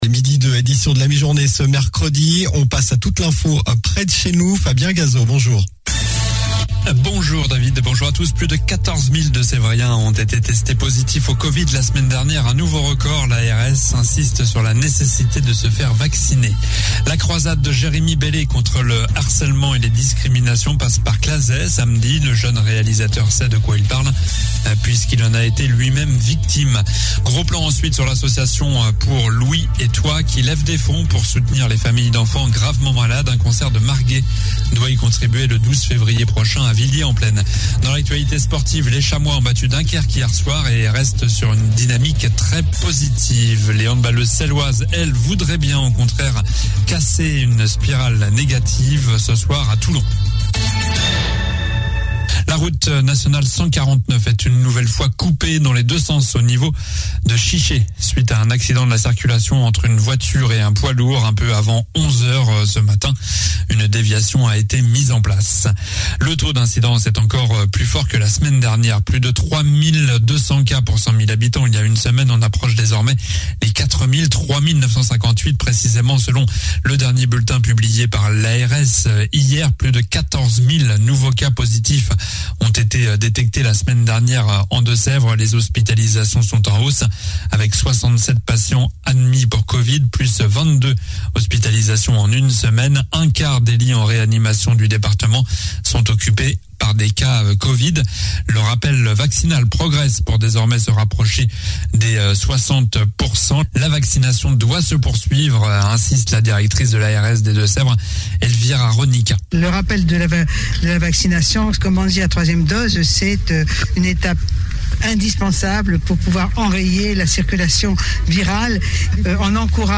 COLLINES LA RADIO : Réécoutez les flash infos et les différentes chroniques de votre radio⬦